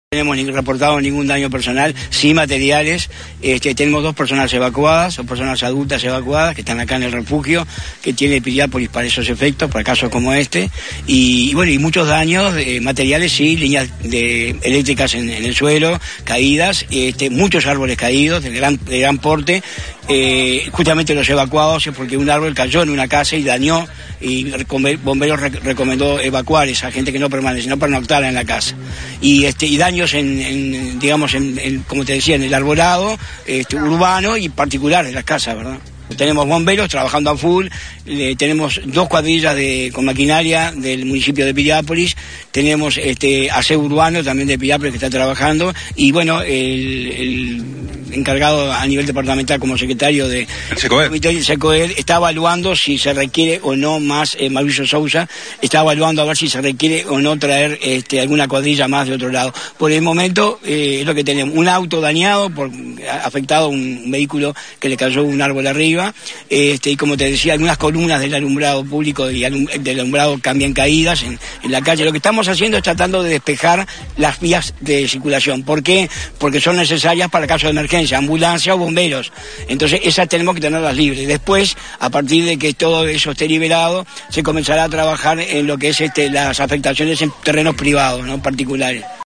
Escuchamos al Alcalde de Piriápolis Carlos Fuentes